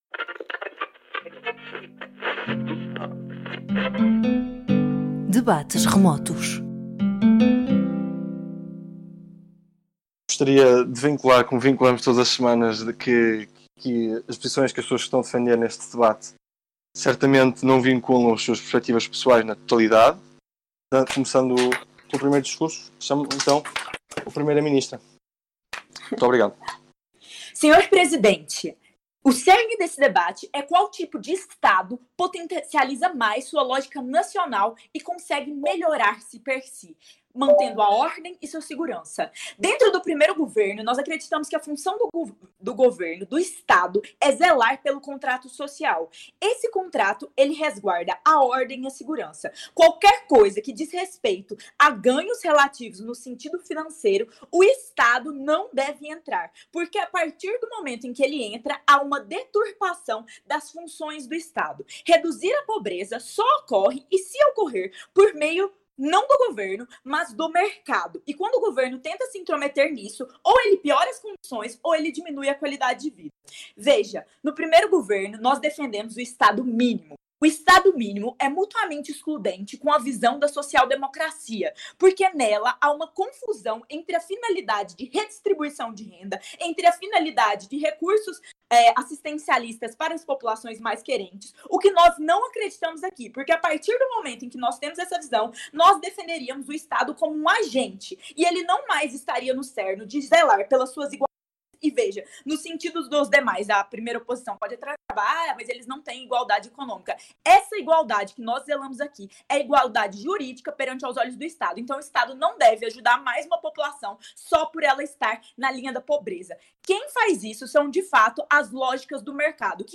É função do Estado reduzir a pobreza relativa dos cidadãos? Siga as linhas de argumentação dos participantes neste debate.